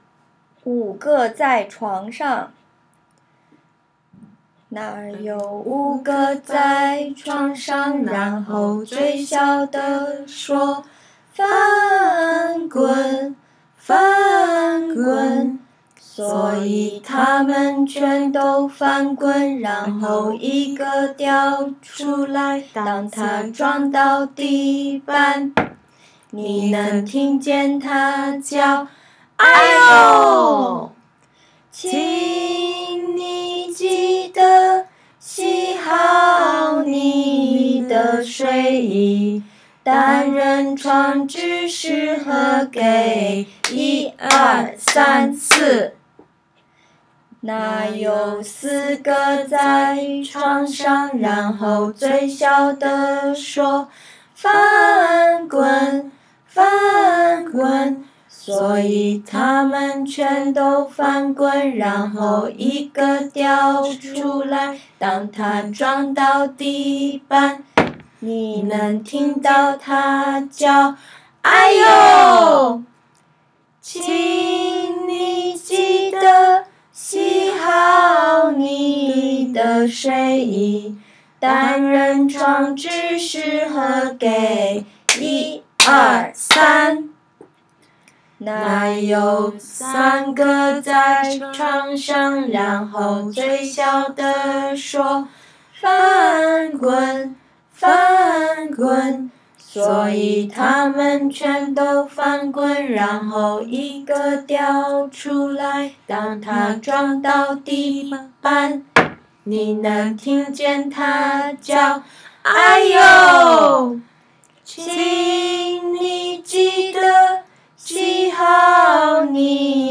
This week, we’ll be starting to learn our Mandarin translated version of this popular under 5’s song.